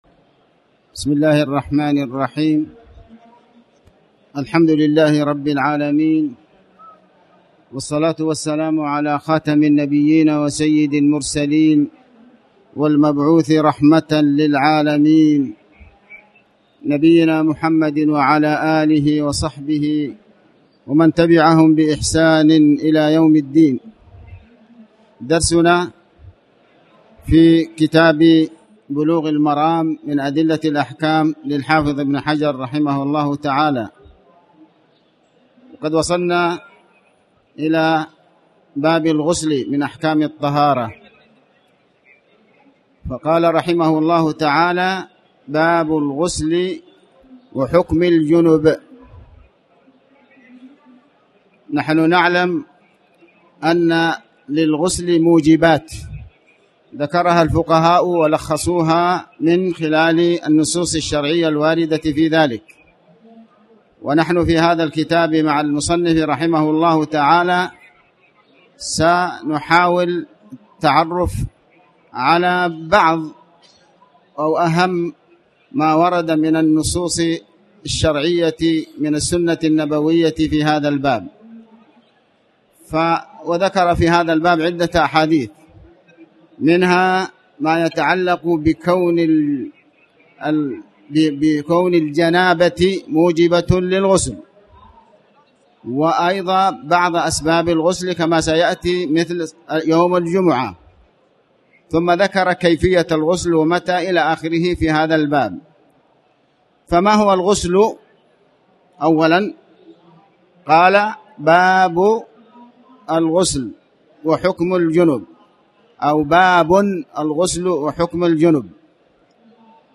تاريخ النشر ٨ محرم ١٤٣٩ هـ المكان: المسجد الحرام الشيخ